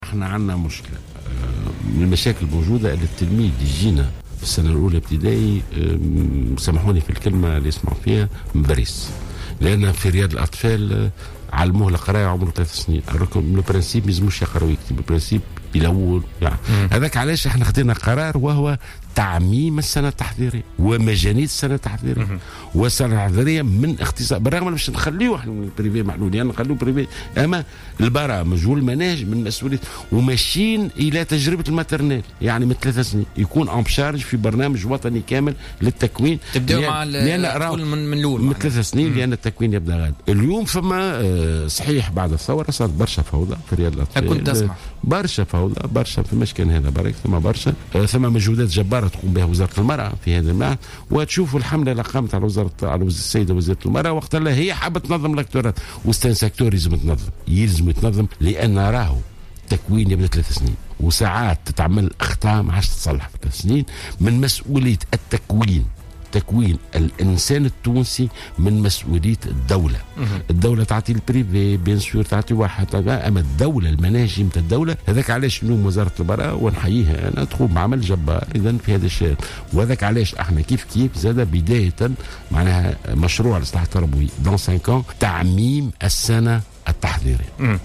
قال وزير التربية ناجي جلول في تصريح للجوهرة أف أم اليوم الجمعة 22 أفريل 2016 إن الوزارة ذهبت إلى تعميم السنة التحضيرية ومجانيتها مع الإبقاء على المدارس الخاصة لعدة أسباب.